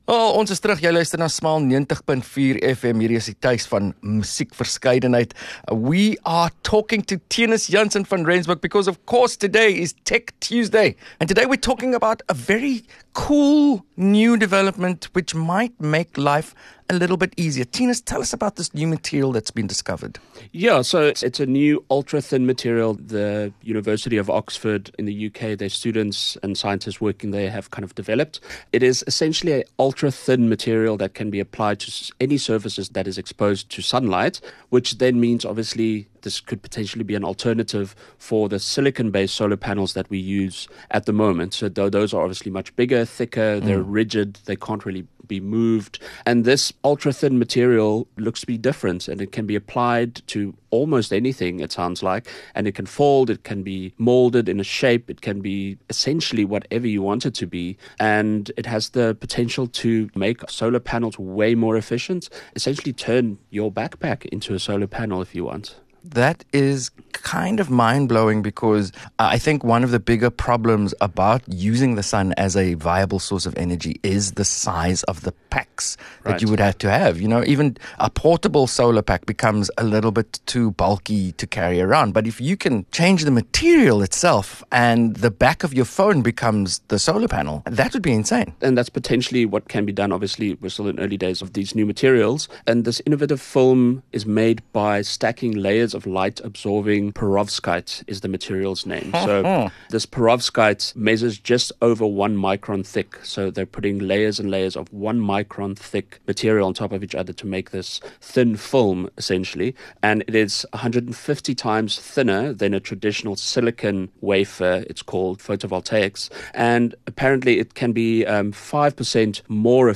Radio Life & Style